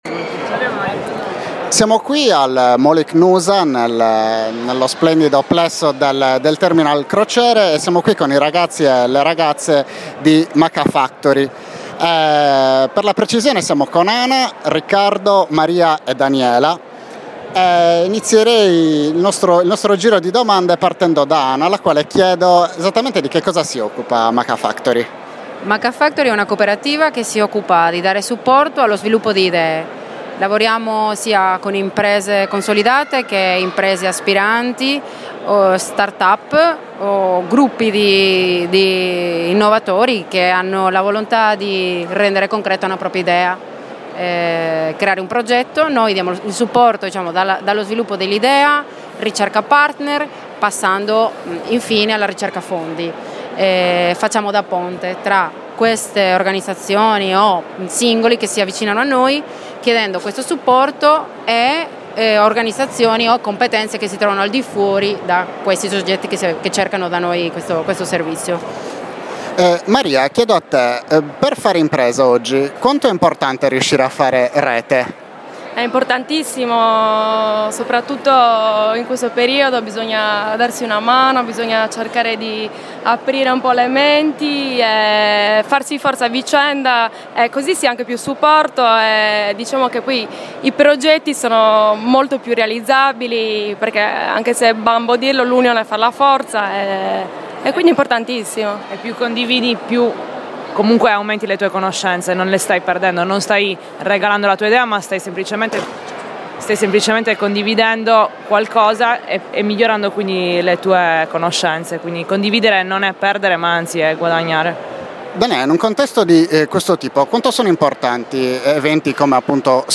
SINNOVA 2015 :: INTERVISTA AI RAGAZZI DI MAKA FACTORY